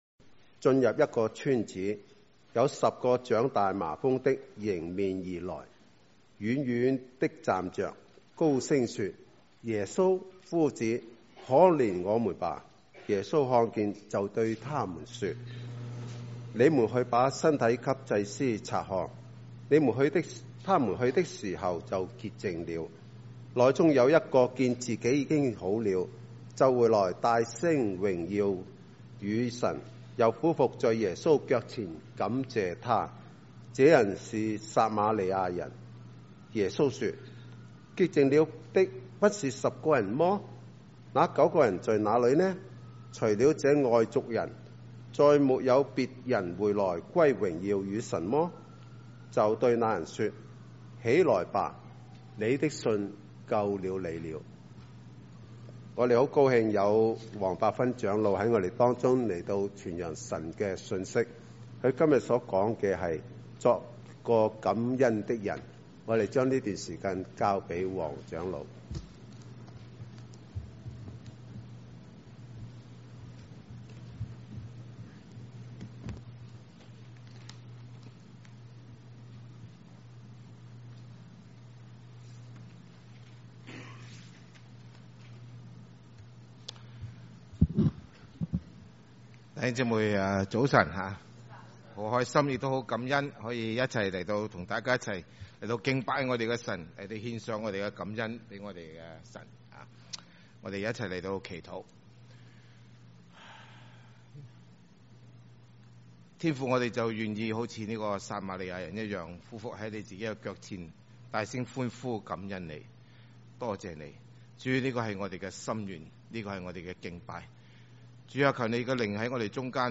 華埠粵語二堂